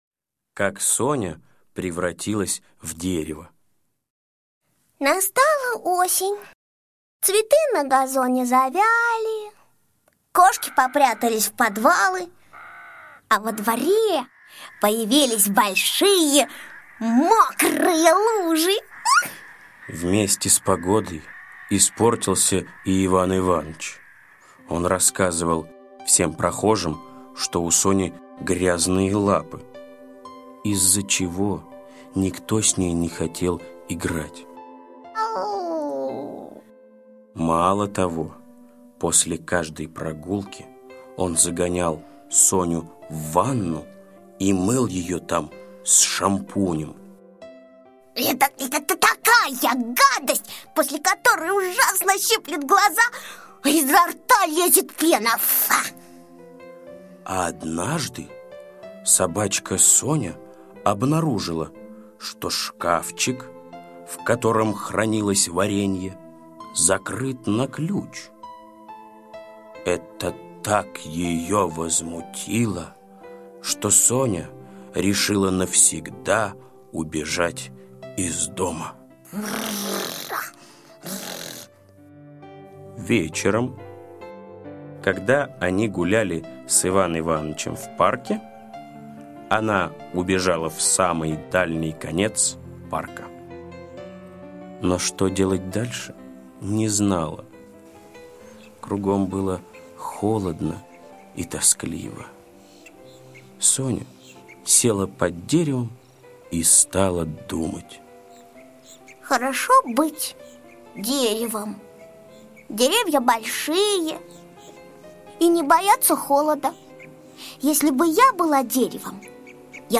Слушайте Как Соня превратилась в дерево - аудиосказка Усачева А.А. Про собачку Соню, которая обиделась на хозяина и решила остаться в парке.